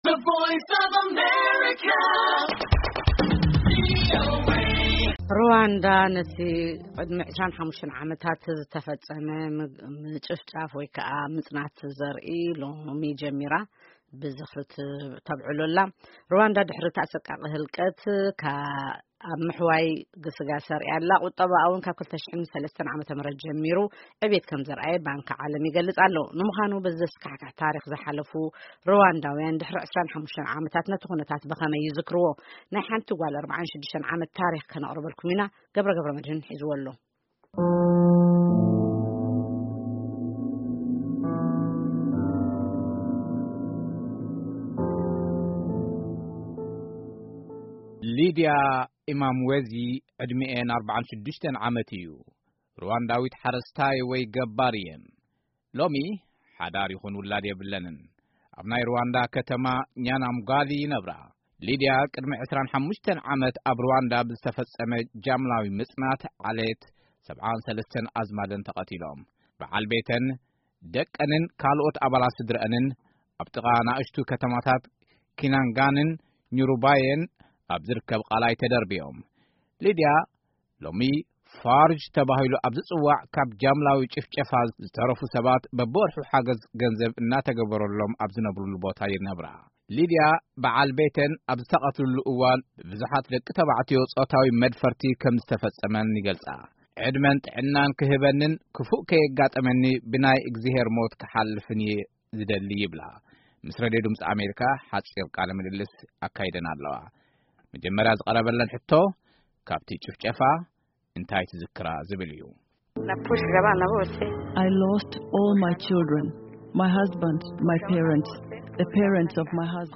ምስ ሬድዮ ድምፂ ኣሜሪካ ቋንቋ እንግሊዝኛ ሓፂር ቃለ ምልልስ ኣካይደን ኣለዋ።